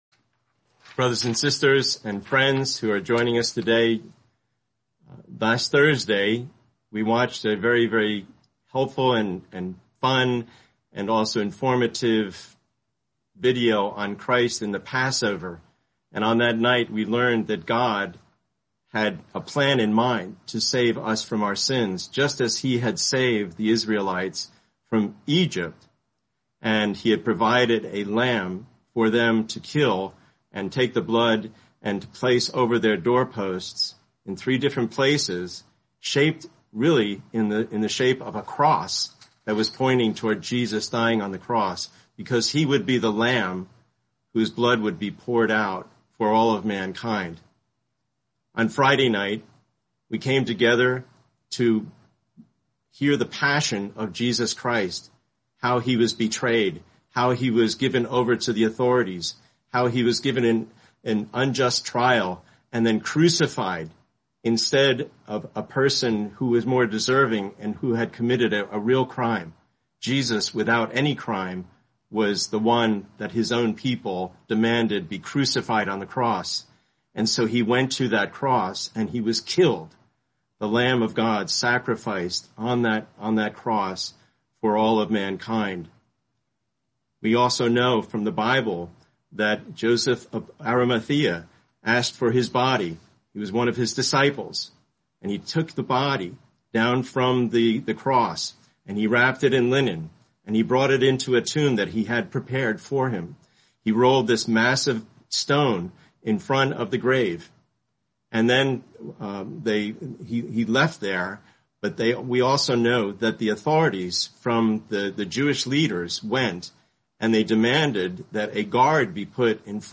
Video: Sunday English Worship Video